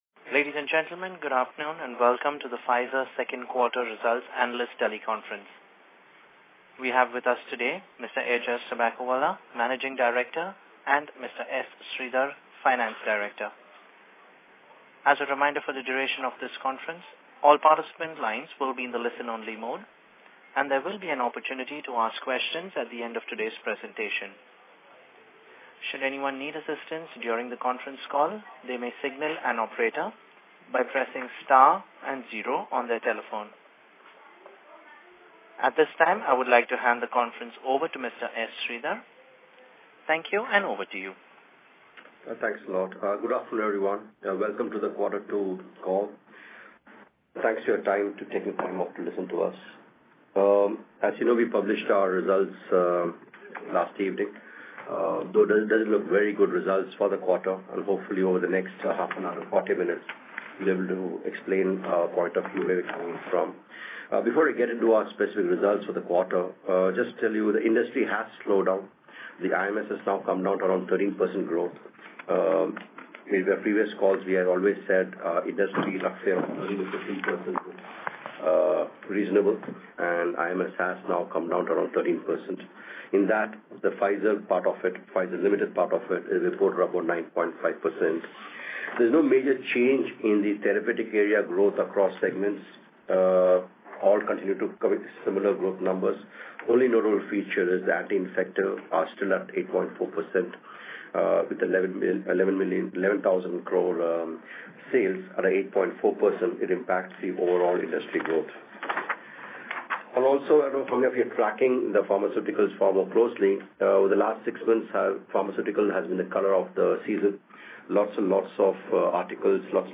Analysts Teleconference